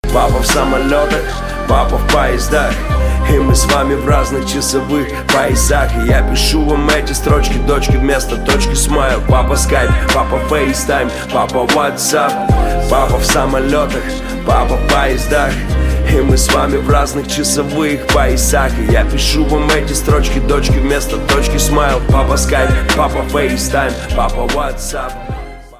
• Качество: 320, Stereo
мужской вокал
русский рэп